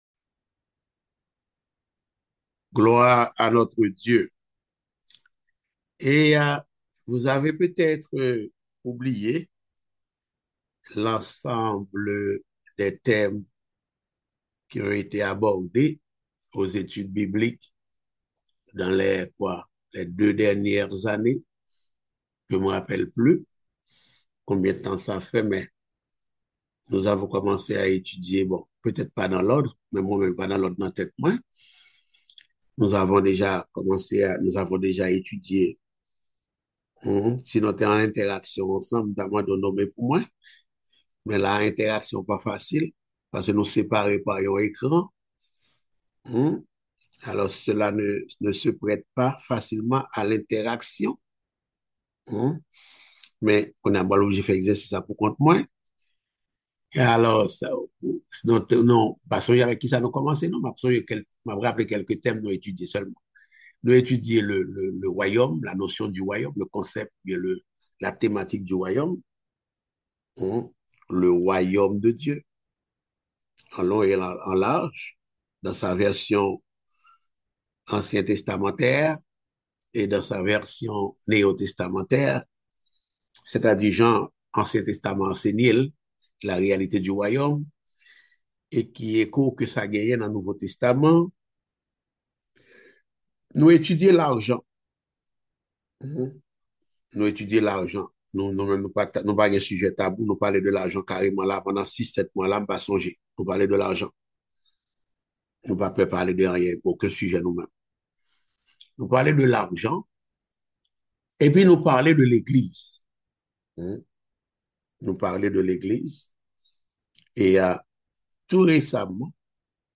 Passage: Hébreux 5.12-14; 1 Corinthiens 3.1-3 Type De Service: Études Bibliques